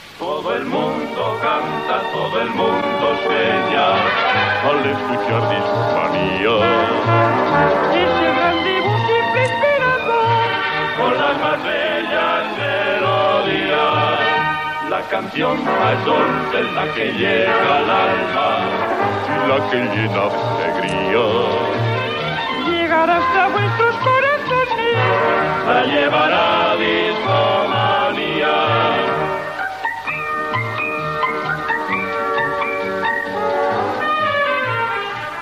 Jingle cantat del programa
Musical